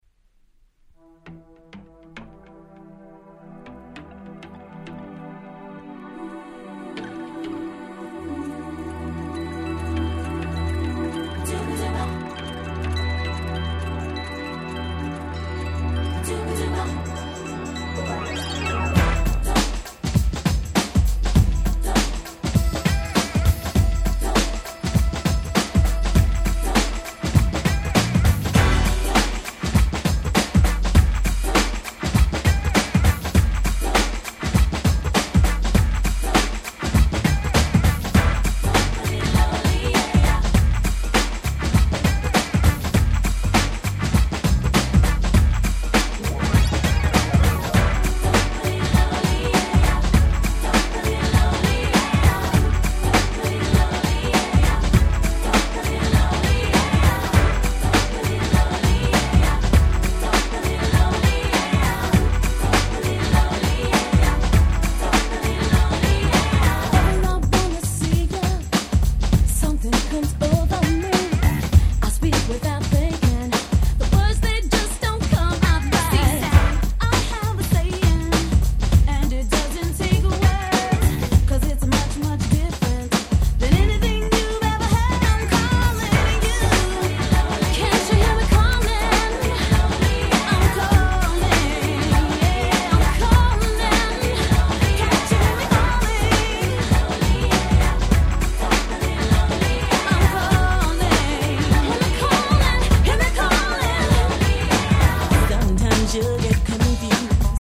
92' Nice Girls New Jack Swing !!
ハネたBeatにエモーショナルな女性ボーカル！